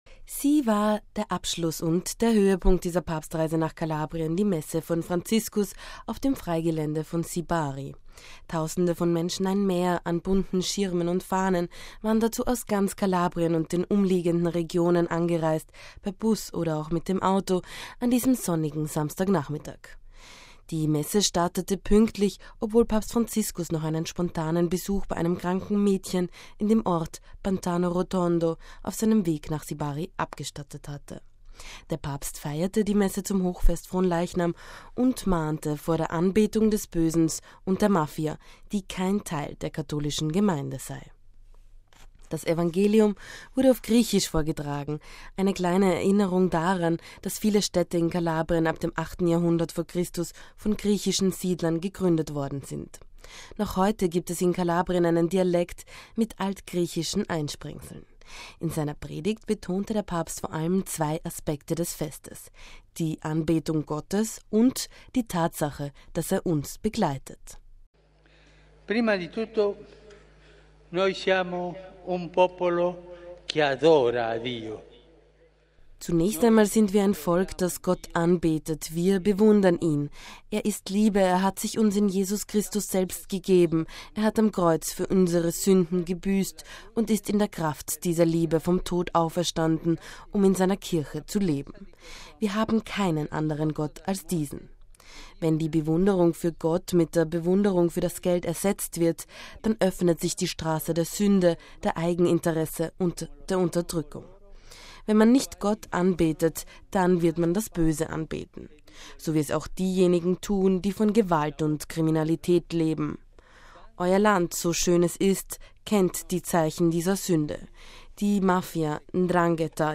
MP3 Sie war der Abschluss und der Höhepunkt dieser Papstreise nach Kalabrien: die Messe von Franziskus auf dem Freigelände von Sibari.